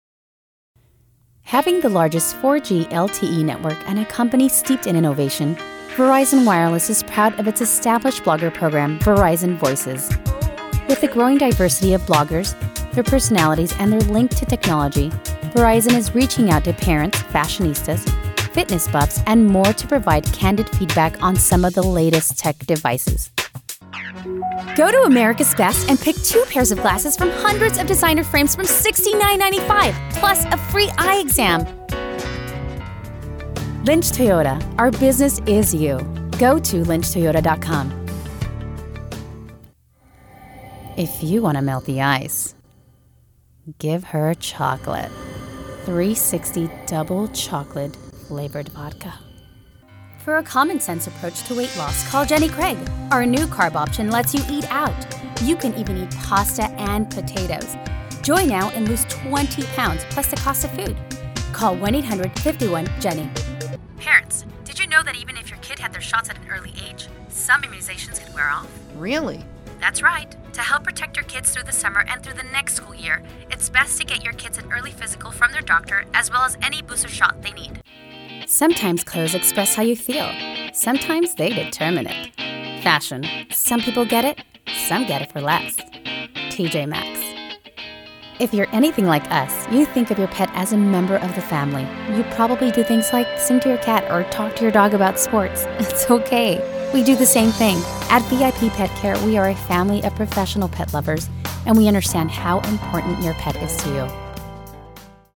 Female Voice Over, Dan Wachs Talent Agency.
Bilingual Voice Actor.
Commercial - English